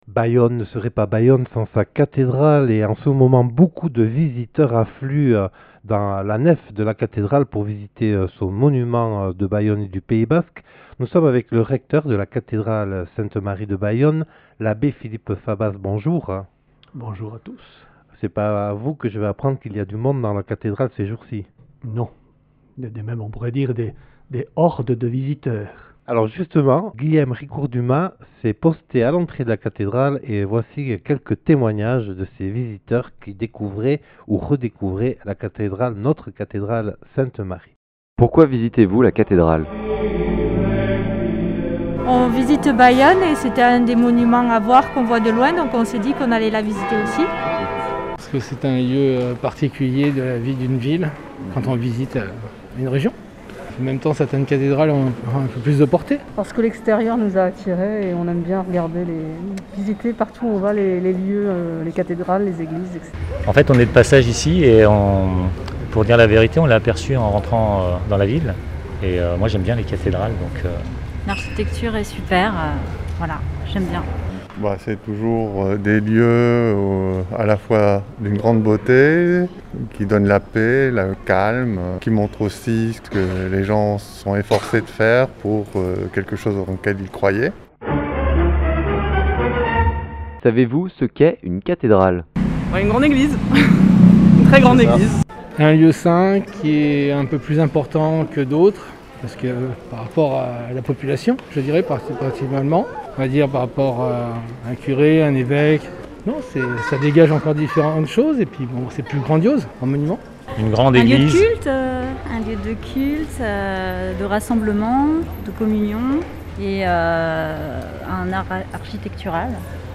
Accueil \ Emissions \ Infos \ Interviews et reportages \ Lancement de la souscription pour la rénovation du Grand orgue de la (...)